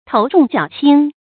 tóu zhòng jiǎo qīng
头重脚轻发音
成语正音 重，不能读作“chónɡ”。